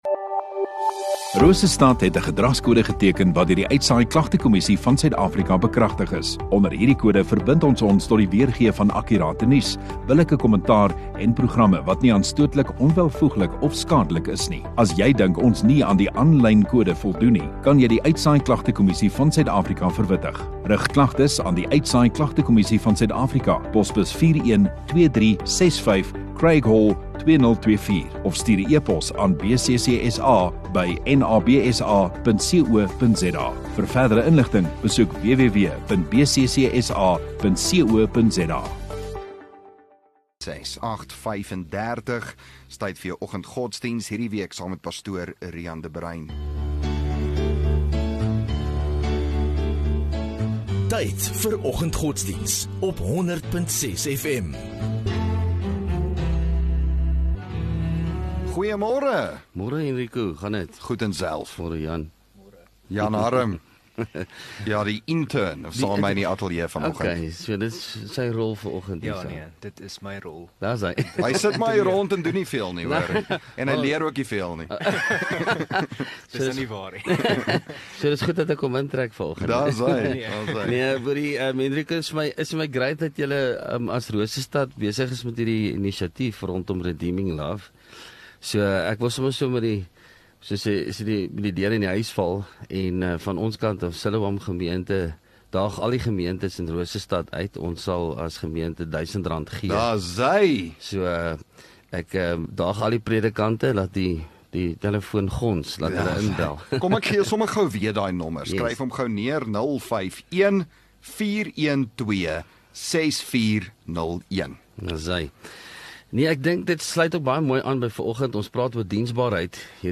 30 Jul Woensdag Oggenddiens